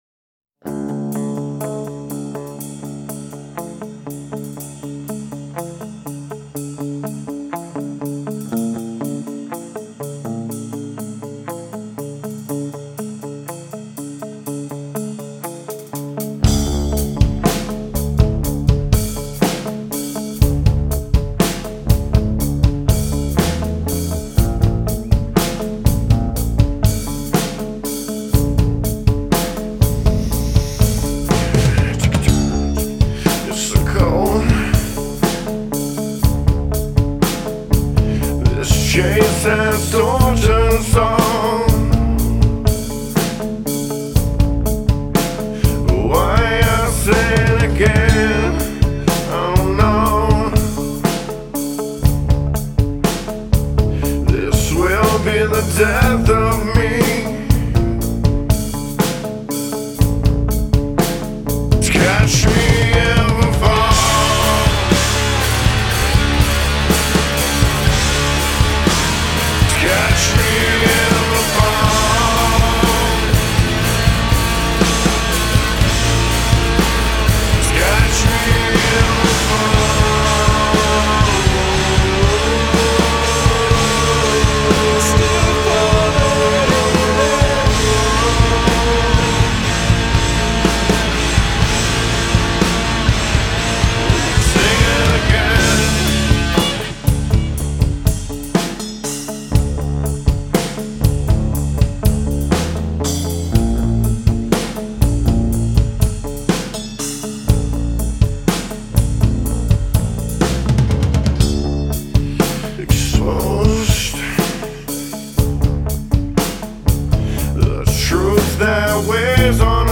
Hi All ! im currently mixing in a new room and curious if this sounds ok sonic-ally ? im thinking the guitars are too bright in the chorus .. havent mixed the vox yet , no automation etc just a basic mix.